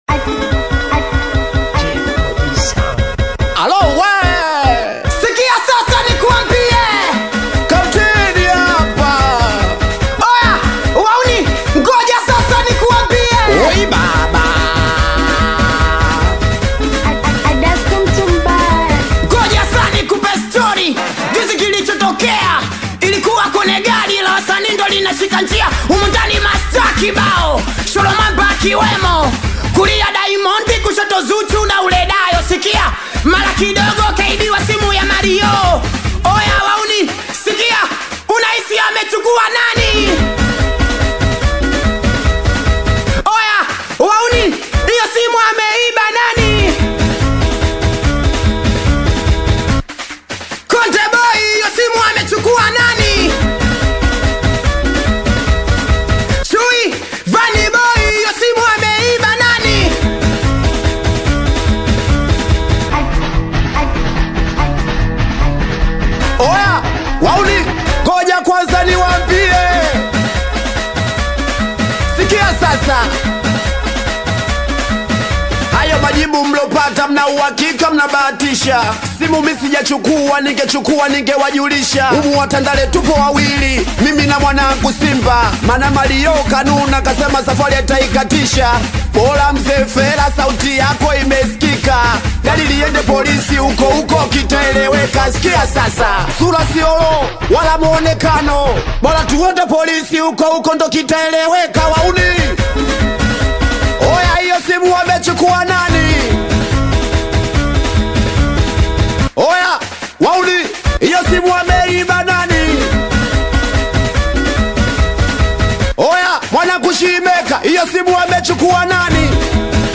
Bongo Flava
Singeli